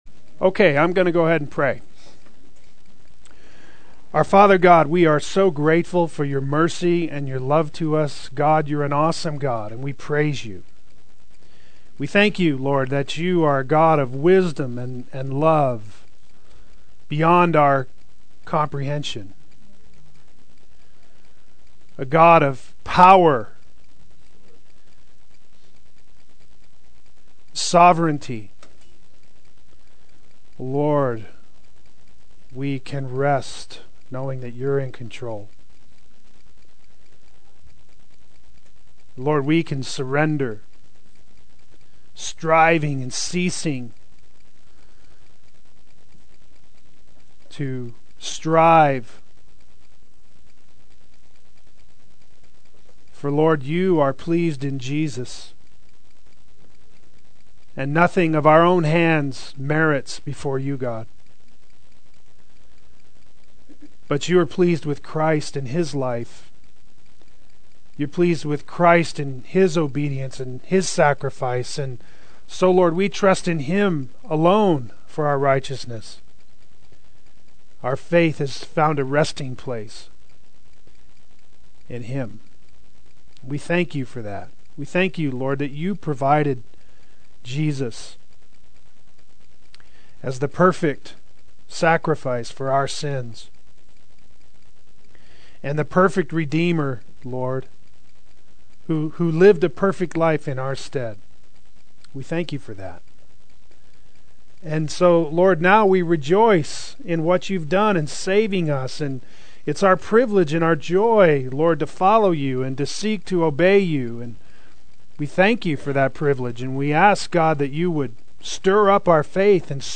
Part 2 Adult Sunday School